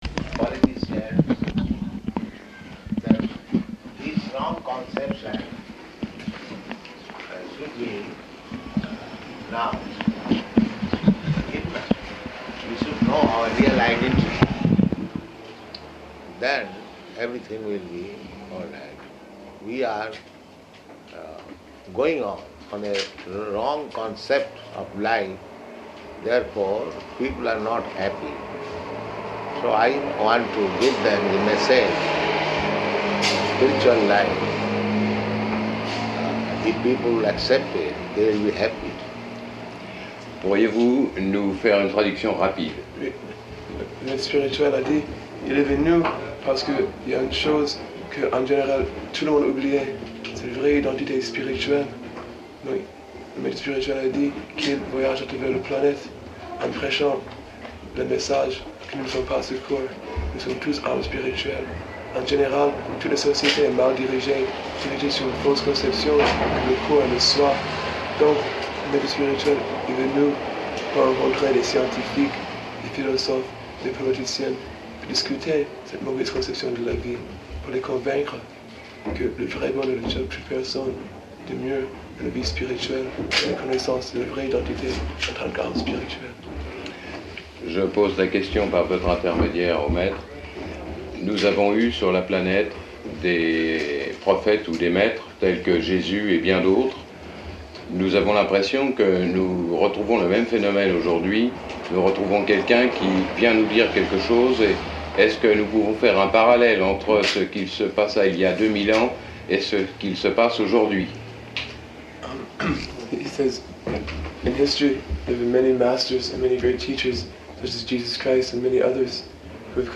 Interview
Interview --:-- --:-- Type: Lectures and Addresses Dated: July 20th 1972 Location: Paris Audio file: 720720I2.PAR.mp3 Prabhupāda: [indistinct] ...this wrong conception should be now.